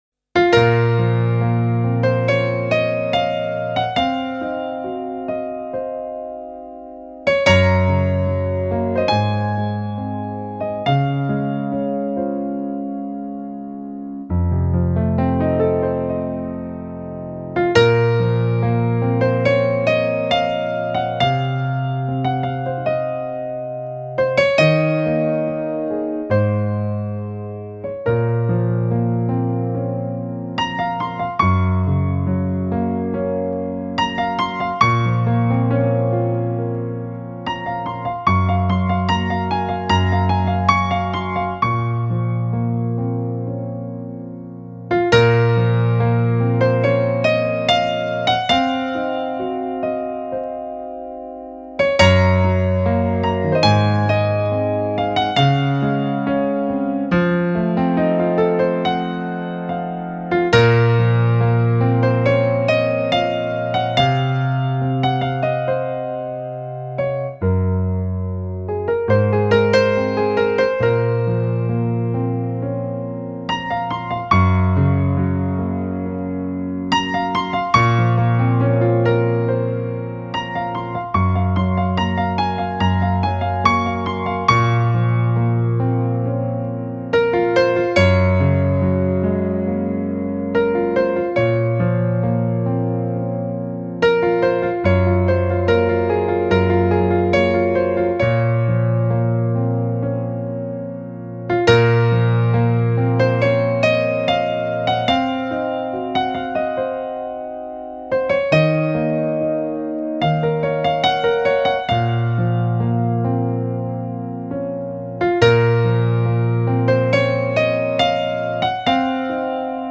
轻柔的演奏就像一涓多情的溪水，透过干净的录音，我们听到一种属于男人的纯情真爱，深刻、贴心，充满感情与非凡的想象力。